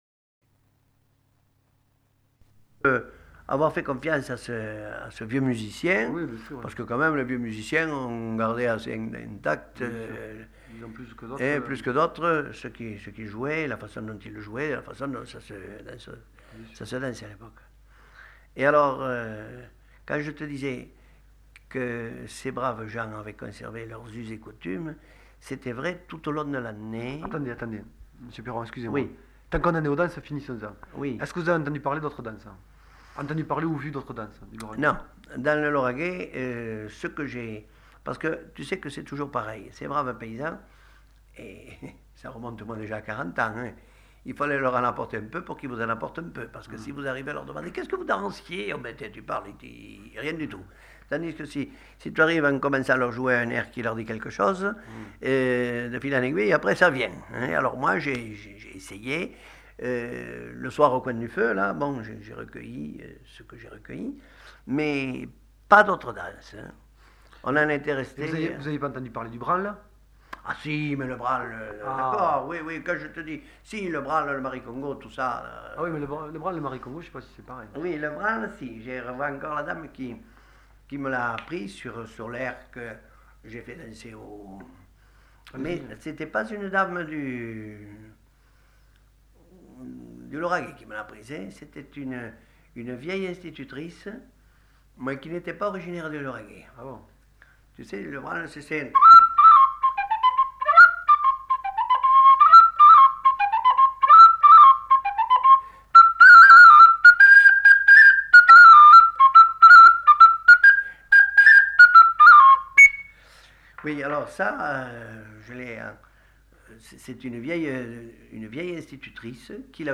Aire culturelle : Lauragais
Lieu : Toulouse
Genre : morceau instrumental
Instrument de musique : flûte
Danse : branle